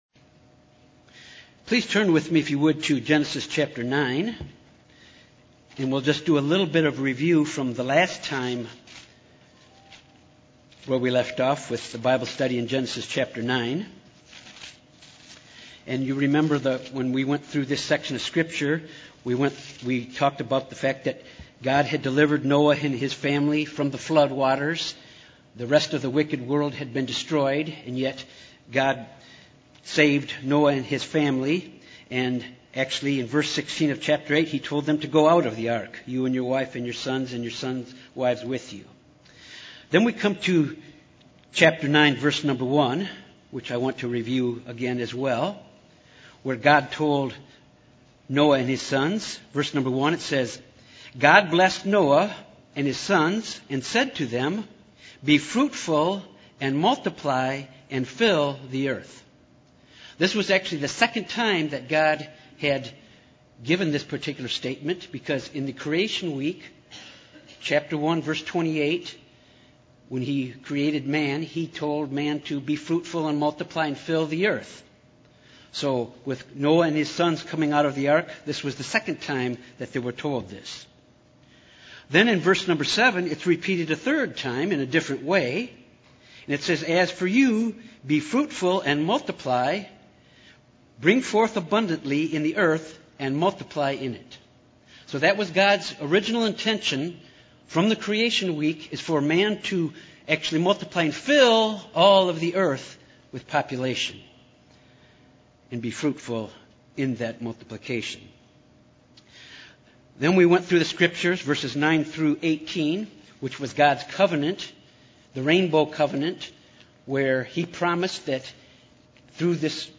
This Bible study focuses on Genesis:9:18 - 11:9. Sons of Noah and their genealogies. The tower of Babel.